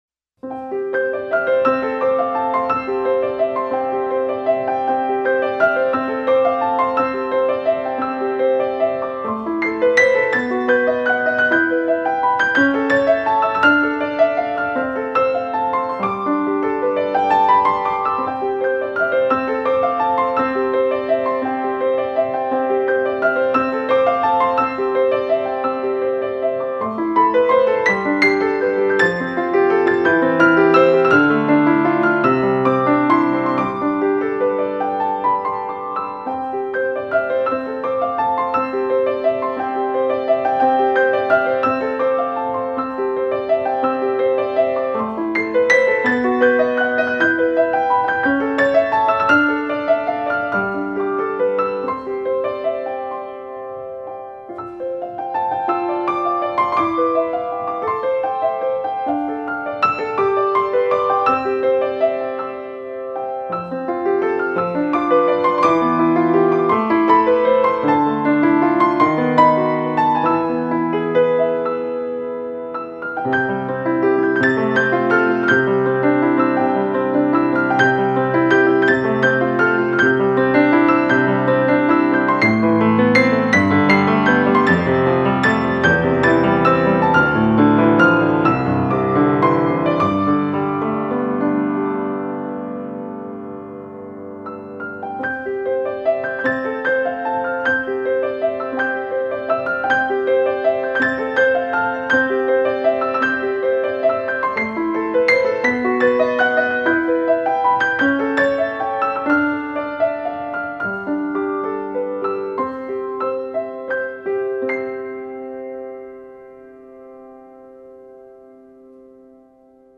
韩国/钢琴
清新宁静